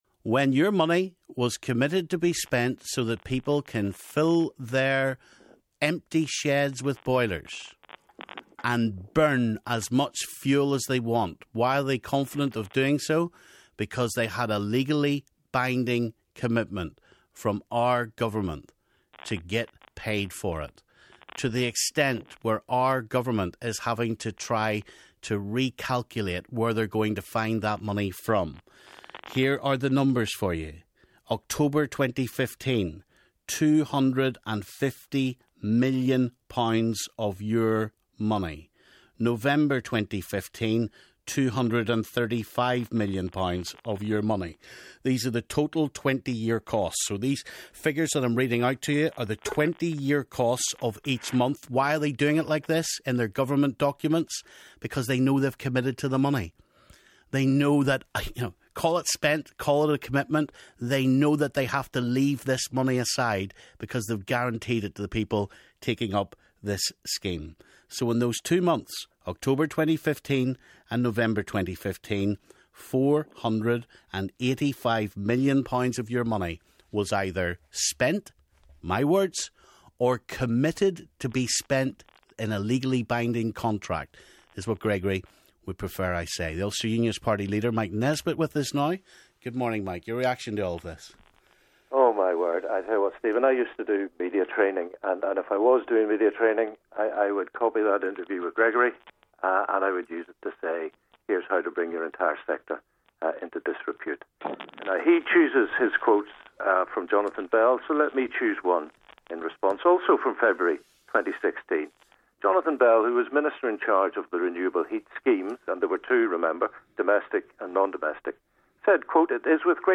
DUP’s Gregory Campbell clashes with Stephen over the ‘Cash for Ash’ scandal (Pt. 2)